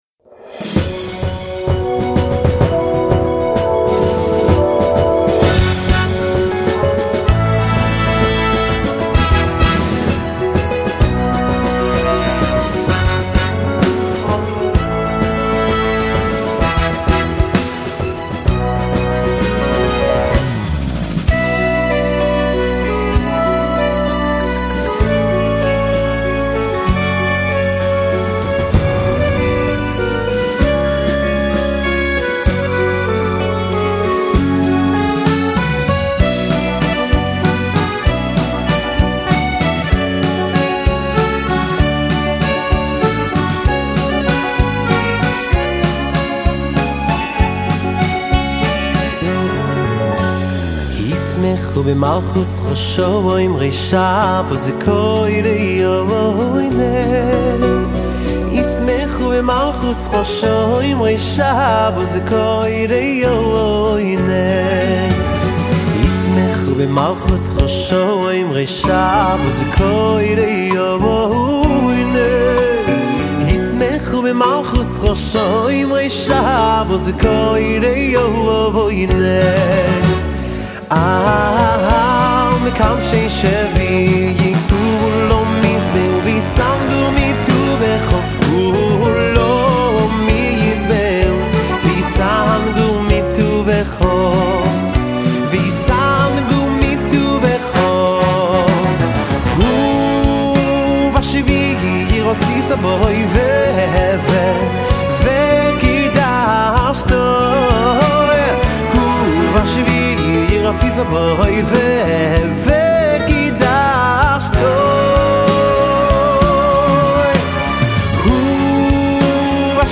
ישמחו במלכותך (ניגון) – חב"דפדיה